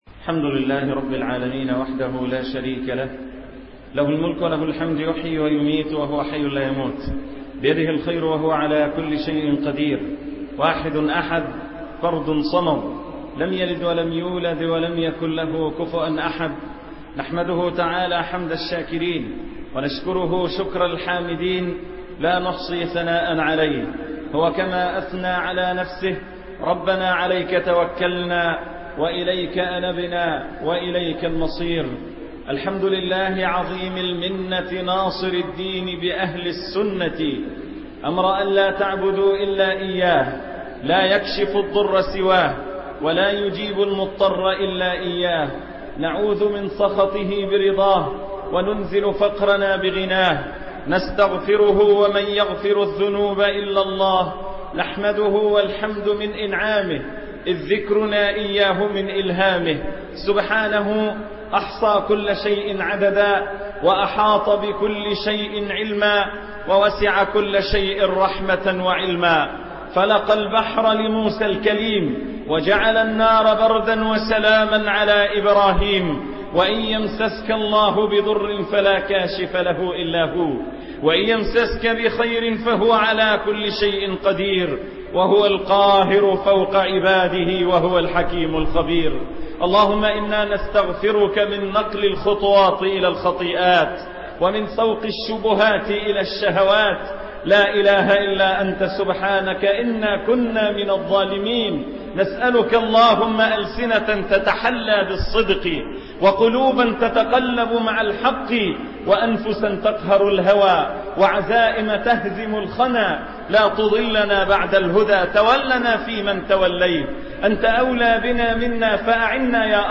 خطب جمعة